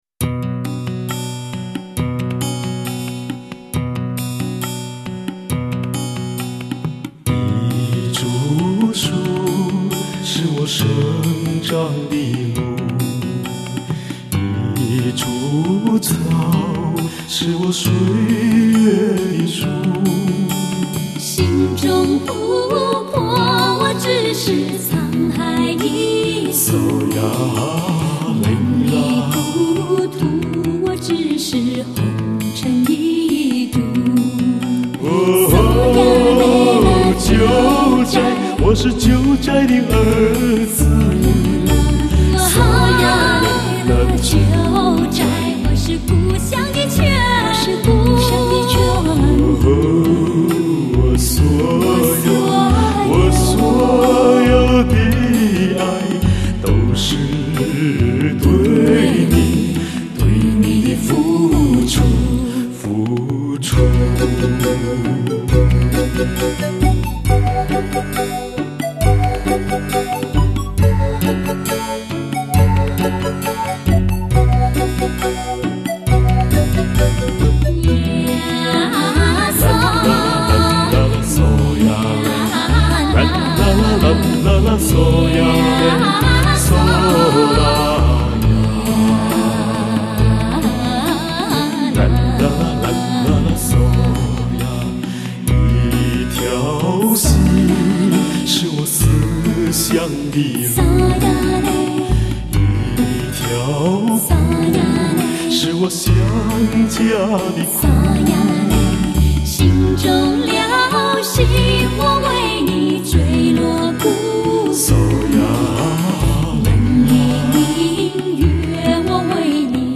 男女声三重唱
歌声像涓涓细流，轻轻缓缓，流进听者的耳中，直灌人们的心田。